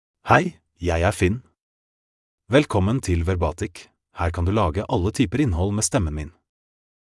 Finn — Male Norwegian Bokmål AI voice
Voice sample
Listen to Finn's male Norwegian Bokmål voice.
Male
Finn delivers clear pronunciation with authentic Norway Norwegian Bokmål intonation, making your content sound professionally produced.